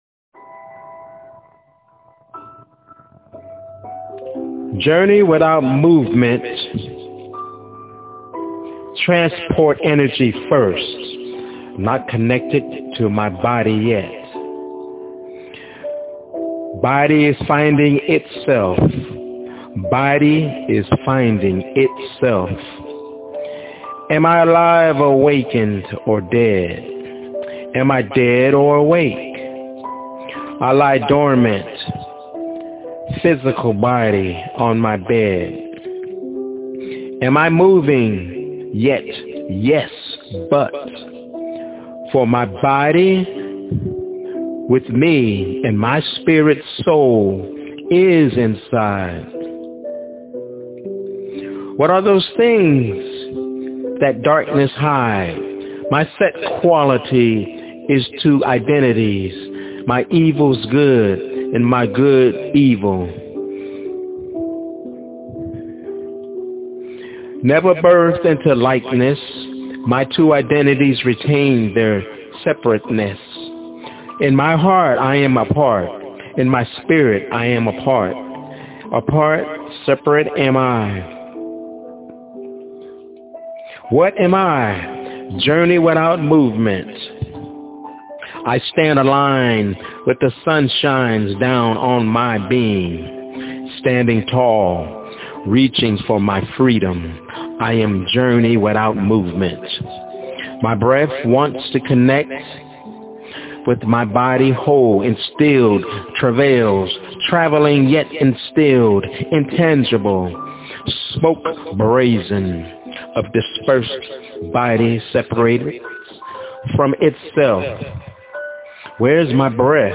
Journey Without Movement- My Spokenword-